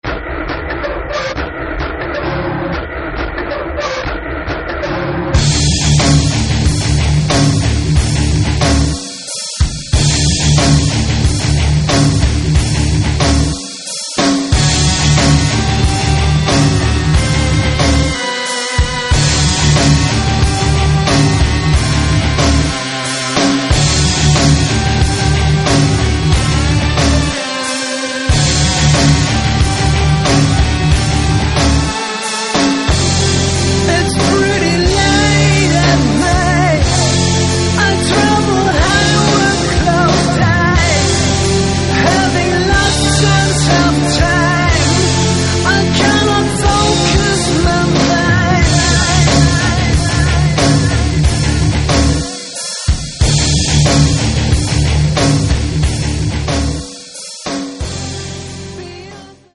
Рок
соло-гитара